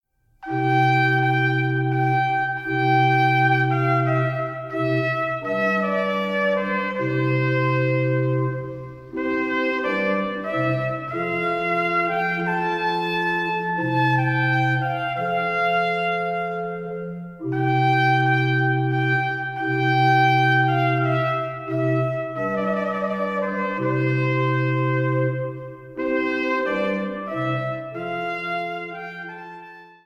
Trumpet
Organ